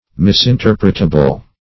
Search Result for " misinterpretable" : The Collaborative International Dictionary of English v.0.48: Misinterpretable \Mis`in*ter"pret*a*ble\, a. Capable of being misinterpreted; liable to be misunderstood.